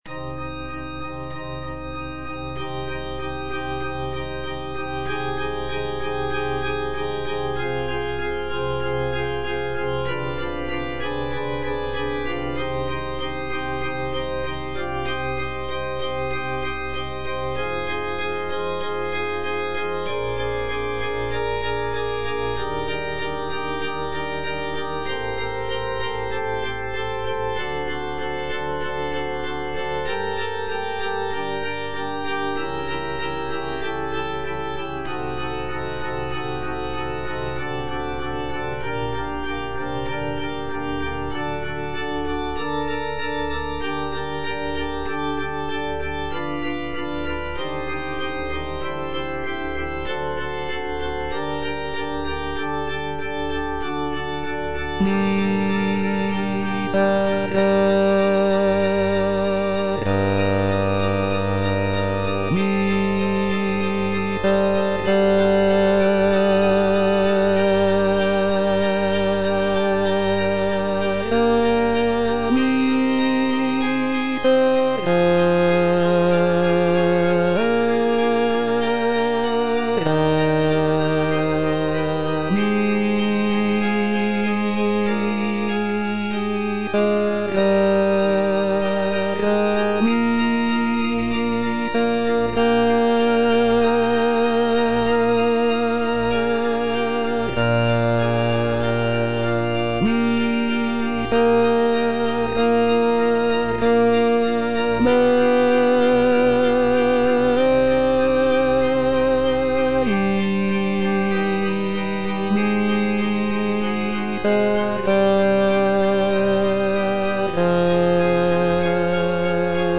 J.D. ZELENKA - MISERERE IN C ZWV57 Miserere I - Bass (Voice with metronome) Ads stop: auto-stop
"Miserere ZVW57" is a sacred choral composition by Jan Dismas Zelenka, a Czech composer of the Baroque era.